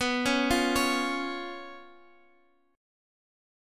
Bsus2b5 chord